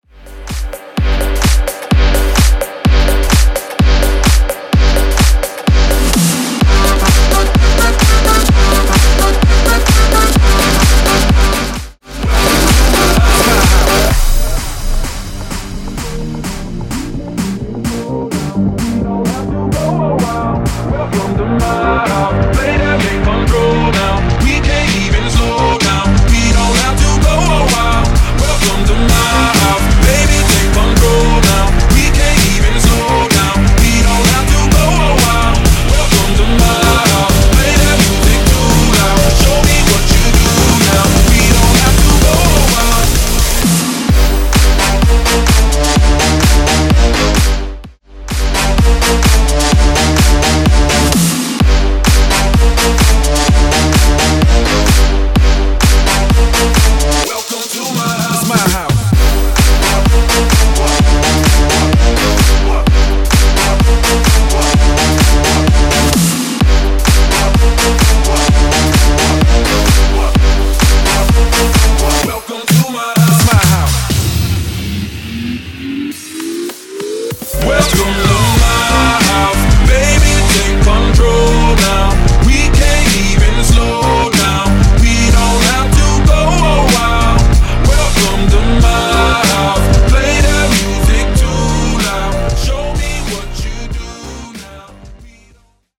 Genre: HIPHOP
Dirty BPM: 128 Time